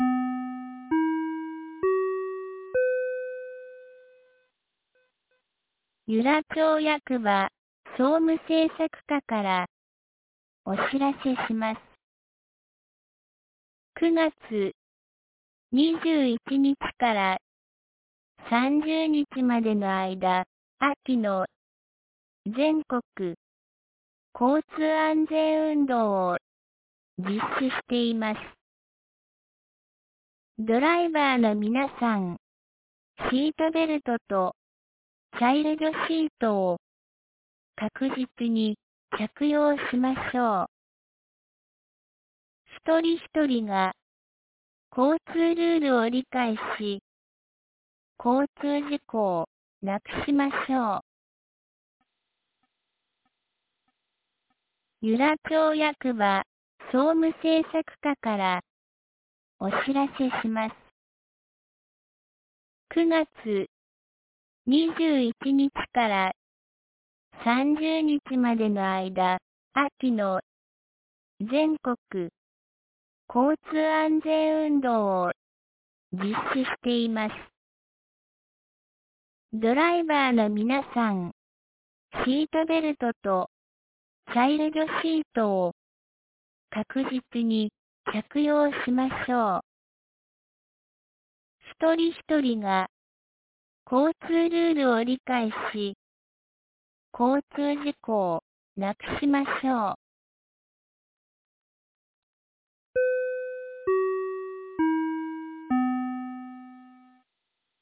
2025年09月24日 12時22分に、由良町から全地区へ放送がありました。